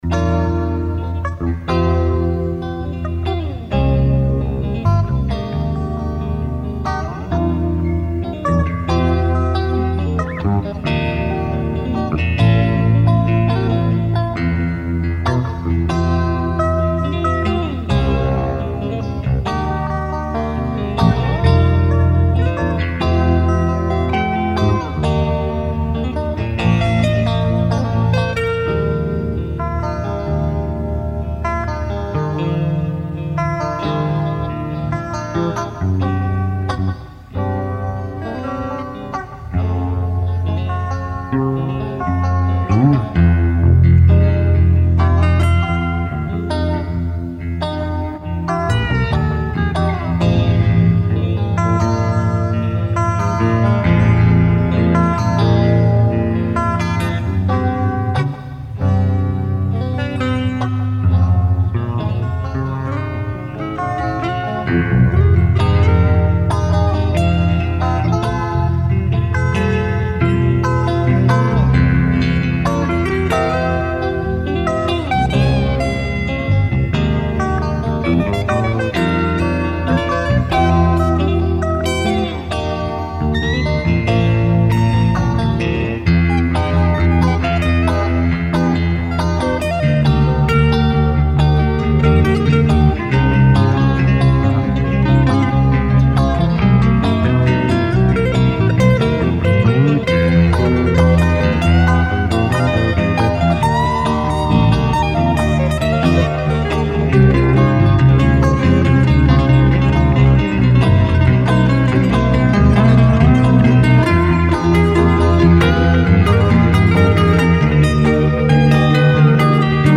Aufgenommen am 22.07.84 in meinem Köngener Wohnzimmer auf Cassette, ergänzt um eine weitere Gitarrenspur via Ping-Pong-Aufnahmetechnik, das war's. Die Aufnahmequalität war nicht schlecht, und das hieß damals Rauschen, Verzerrung und Bandjammern gehörte eben dazu. Mit Hilfe einer modernen Zaubersoftware konnte der Sound in einer Qualität restauriert werden, die mich sprachlos macht.
Electric Guitars
Electric Bass